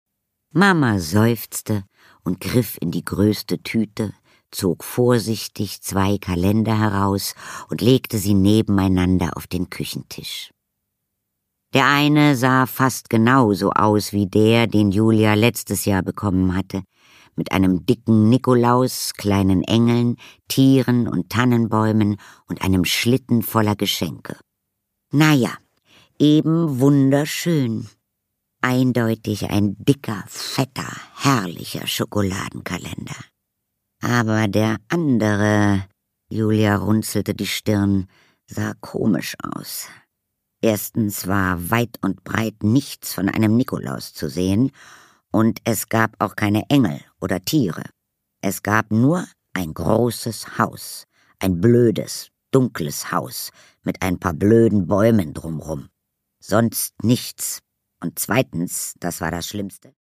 Produkttyp: Hörbuch-Download
Gelesen von: Katharina Thalbach